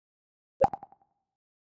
Звуки MacBook Pro и iMac скачать mp3 - Zvukitop